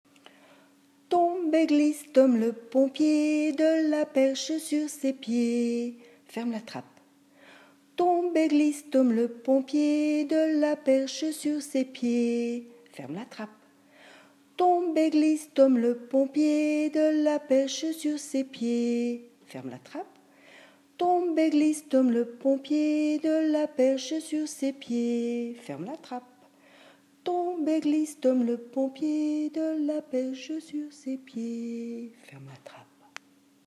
Merci de votre indulgence pour les enregistrements improvisés !
Chanson :